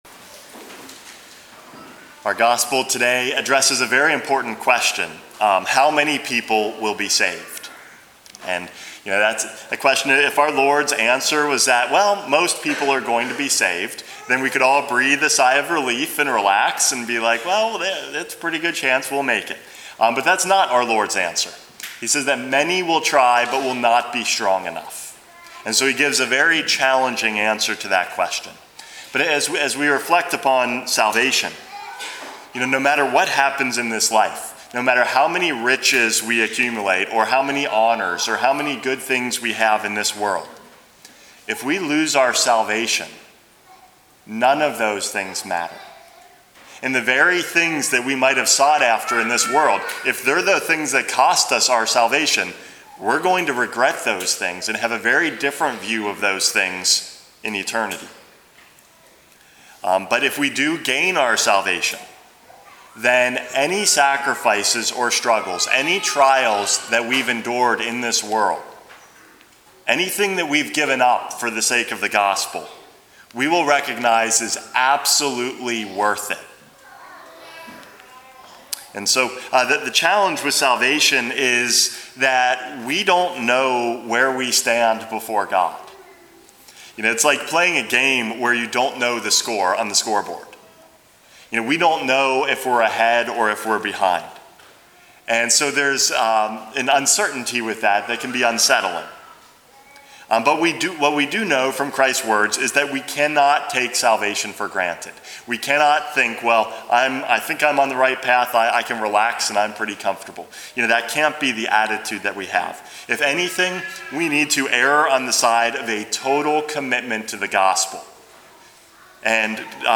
Homily #461 - Help for Salvation